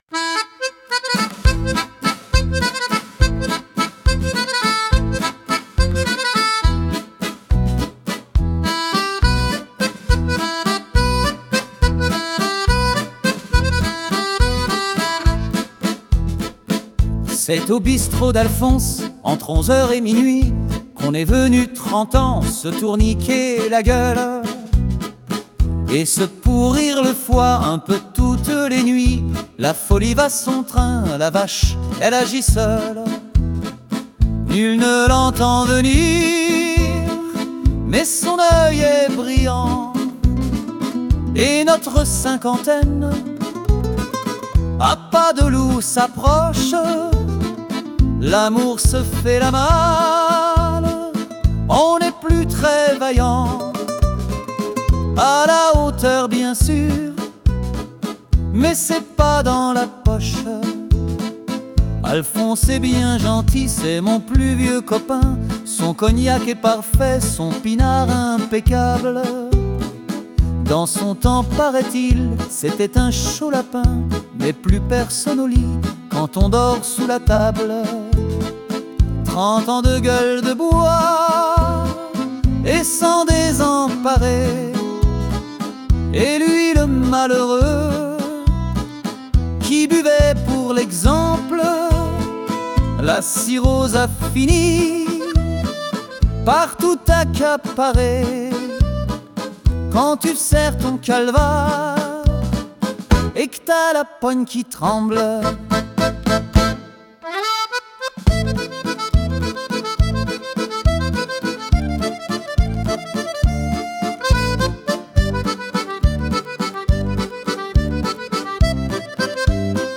Pour les nostalgiques du bal à papa de la valse musette et de l'accordéon.
Parole de Bernard Dimey
Montage et arrangement musical : Suno